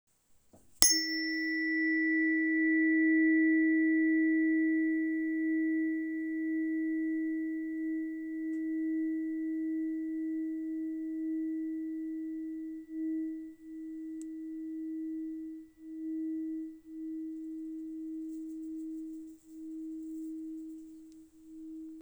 Terapeutická ladička 317,83 Hz Játra hliník CZ
Její teplý, vibračně sytý tón přináší pocit uvolnění, lehkosti a znovunabyté vitality.
• Materiál: hliník
• Frekvence: 317,83 Hz (E♭)
• Každá ladička je ručně laděná a má čistý, dlouhý dozvuk.